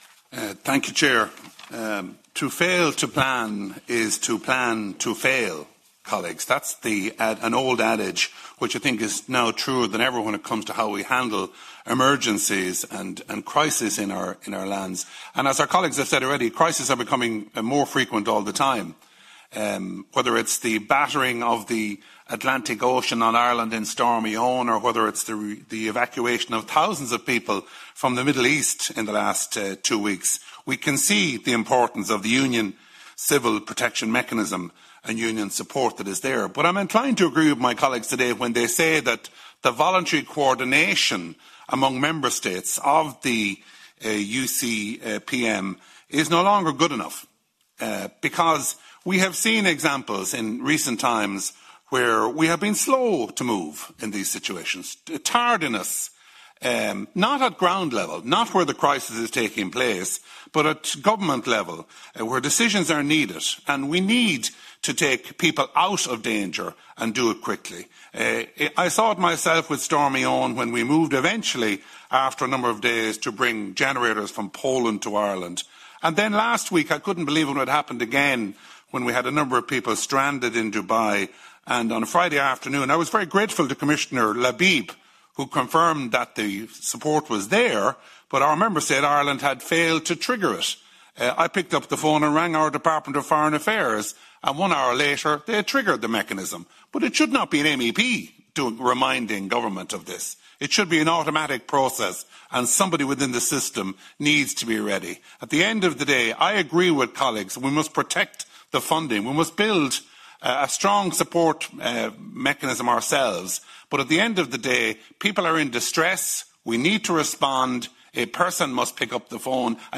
Speaking at a meeting of the European Parliament Regional Development Committee meeting in Brussels yesterday on emergency situations, MEP Mullooly says the Union Civil Protection Mechanism helped Irish people after Storm Éowyn and during last week’s evacuations.